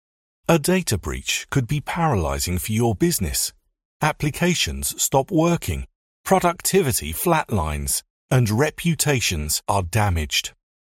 British English VO from London but based in glorious Yorkshire
Data Security Website Video - Adult Male